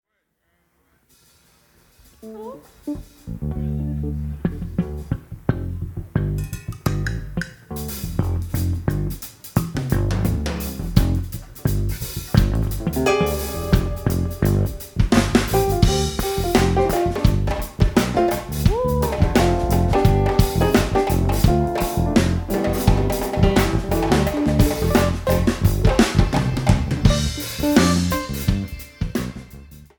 Cajun/Zydeco